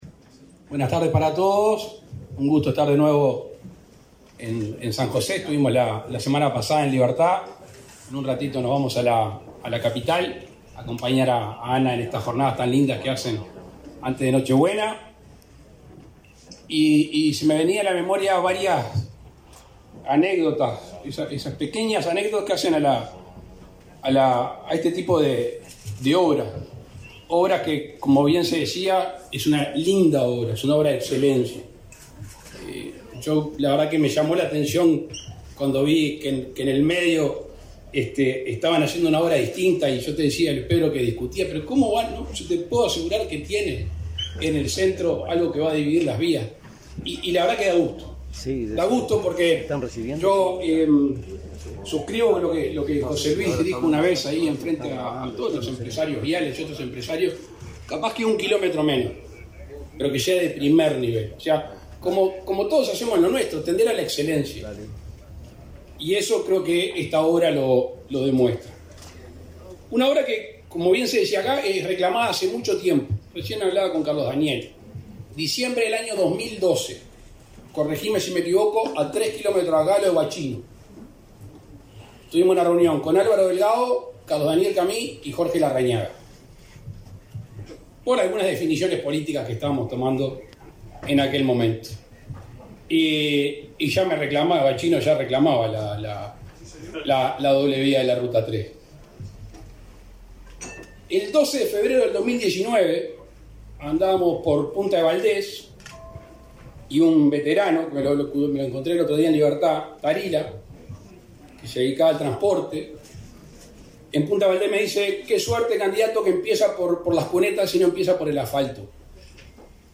Palabras del presidente de la República, Luis Lacalle Pou
Palabras del presidente de la República, Luis Lacalle Pou 23/12/2022 Compartir Facebook X Copiar enlace WhatsApp LinkedIn Con la participación del presidente de la República, Luis Lacalle Pou, se realizó el acto de inauguración de las obras de la primera etapa de la doble vía de la ruta 3, en el departamento de San José.